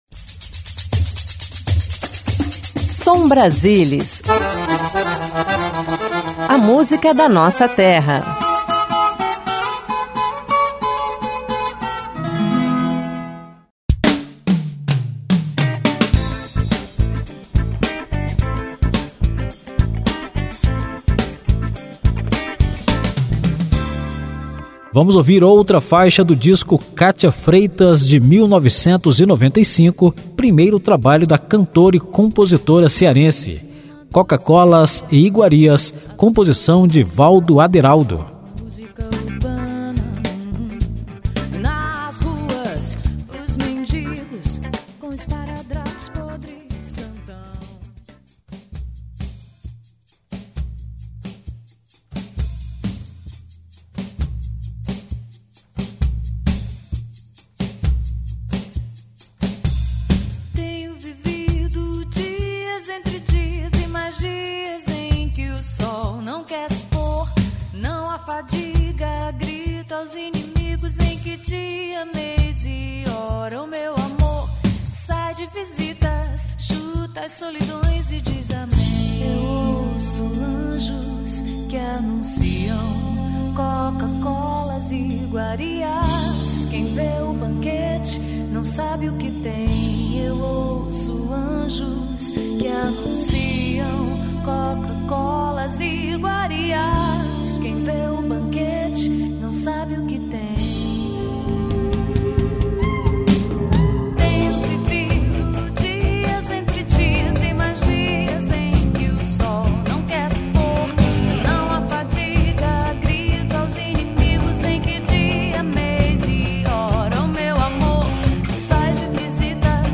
Música Brasileira MPB Canção popular Ritmos nordestinos